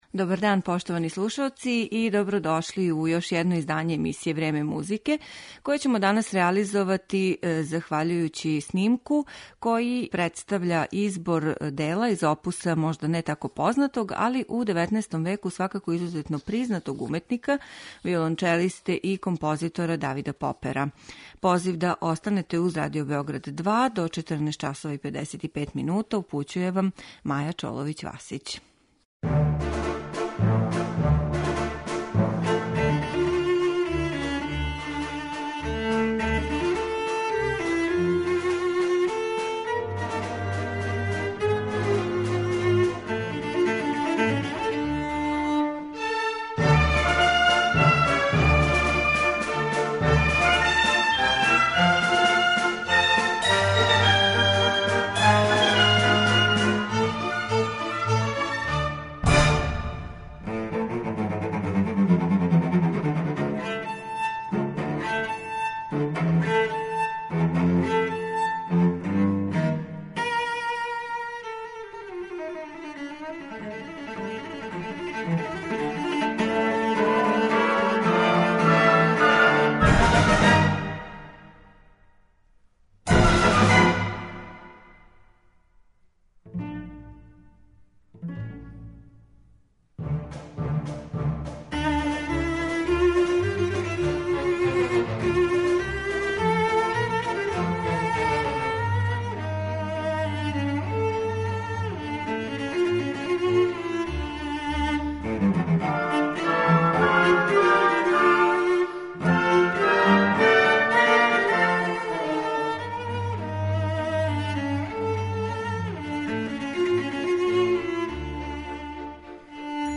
Музика Давида Попера за виолончело